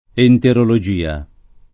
[ enterolo J& a ]